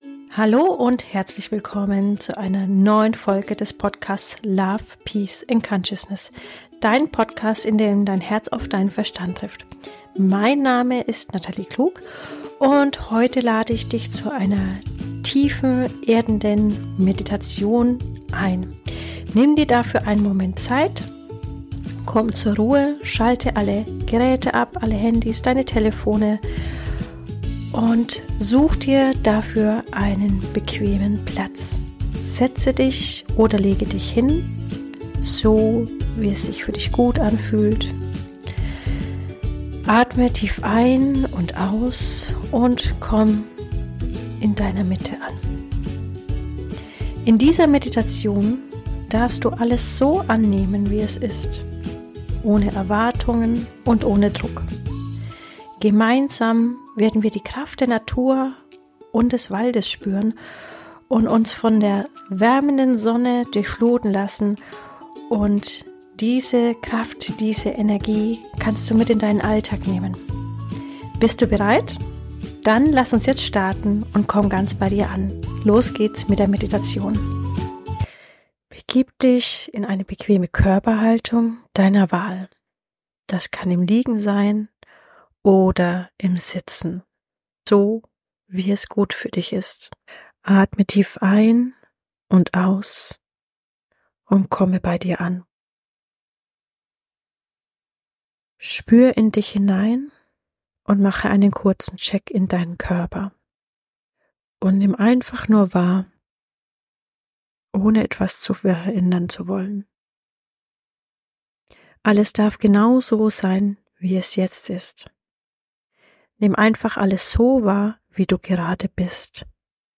Meditation zur inneren Balance - geführte Mediation